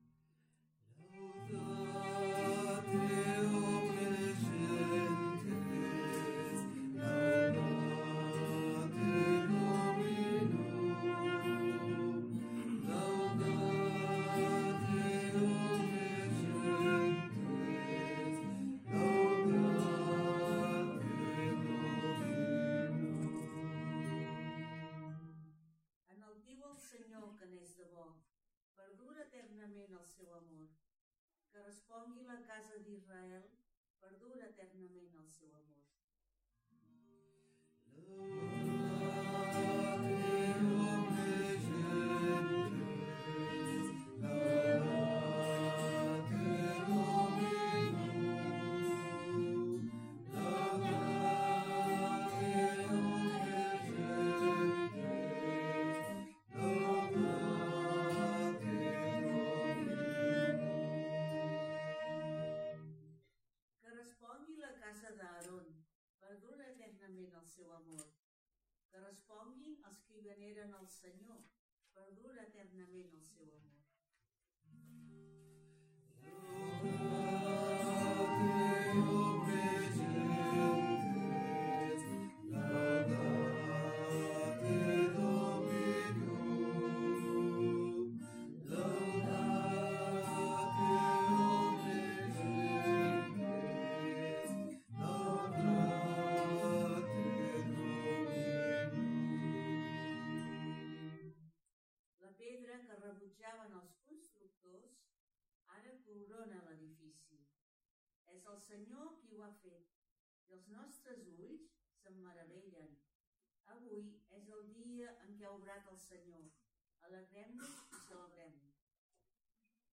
Pregària de Taizé a Mataró... des de febrer de 2001
Capella dels Salesians - Diumenge 27 d'abril de 2025